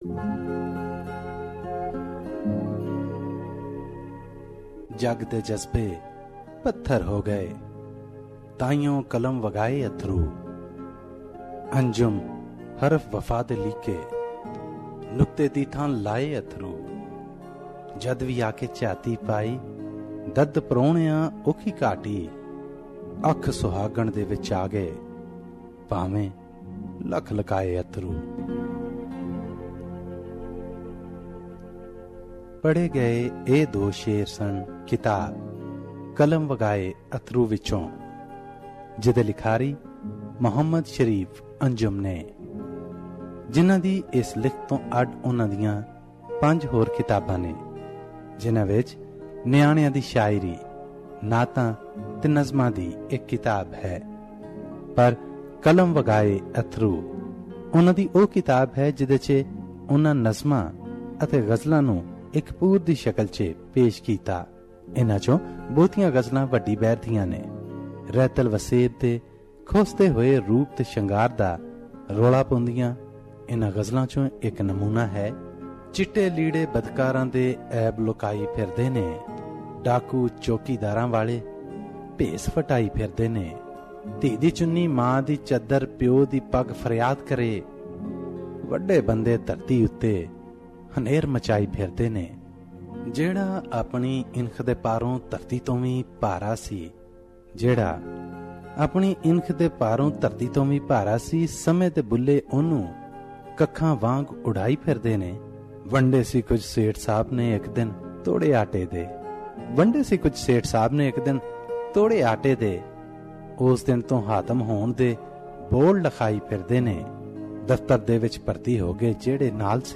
book review